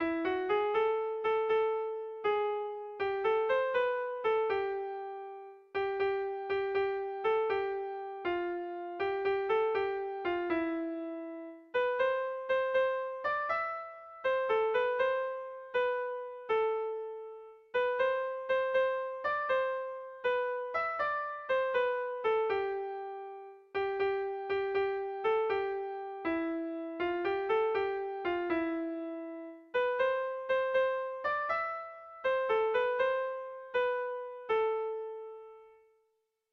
Kontakizunezkoa
Hamabiko txikia (hg) / Sei puntuko txikia (ip)
ABDEBD